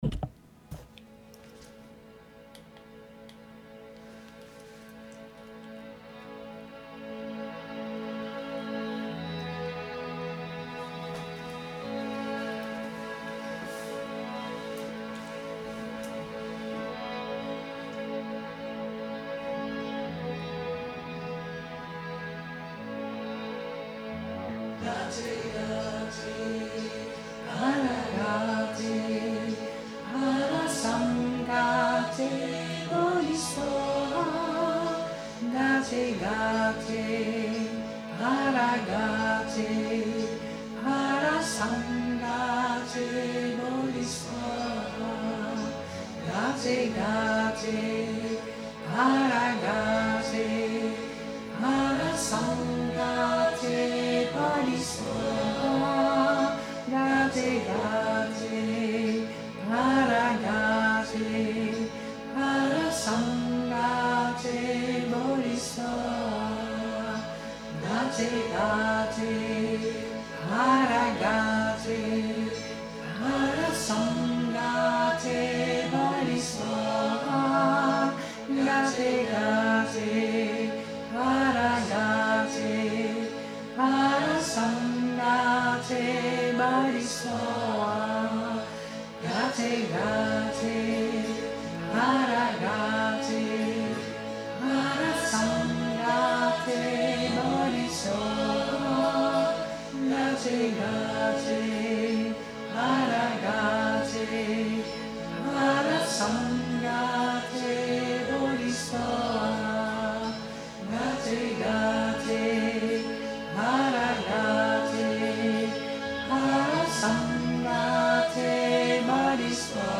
wervelende speelse en helende Klanken